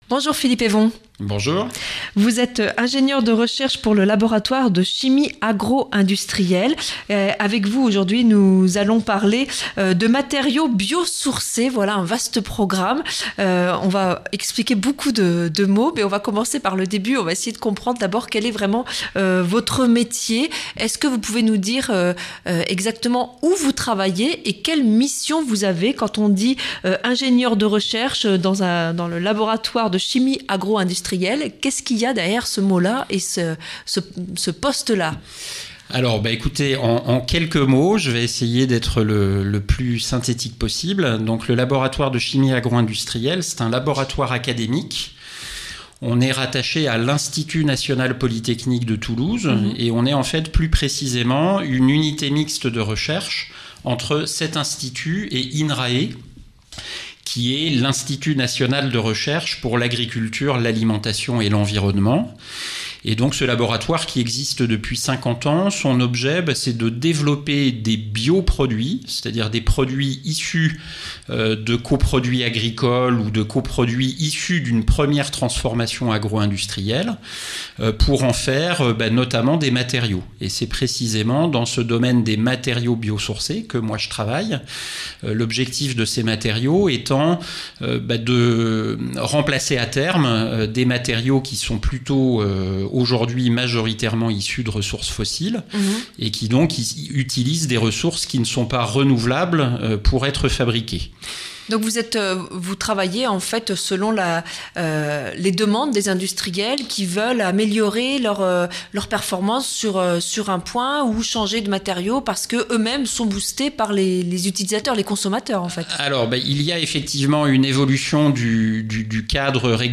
Accueil \ Emissions \ Information \ Locale \ Interview et reportage \ Les biomatériaux : l'avenir ?